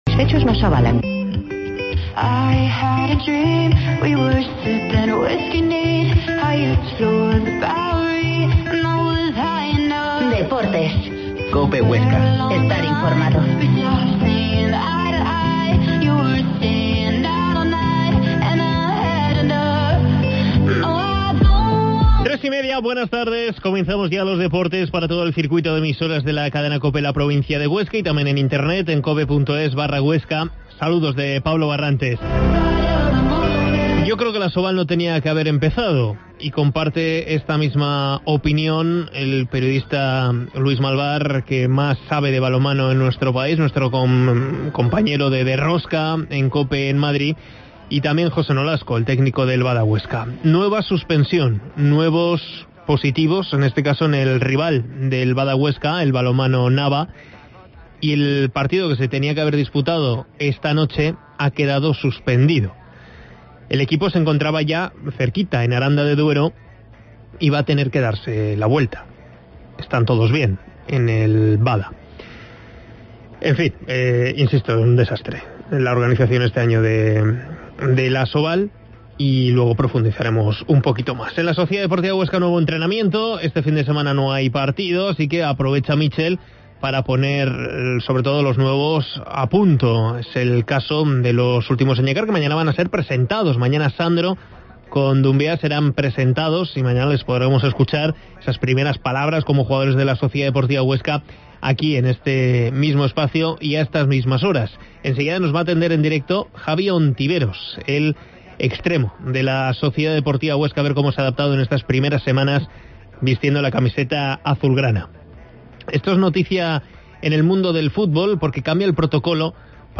Entrevista a Javi Ontiveros en Cope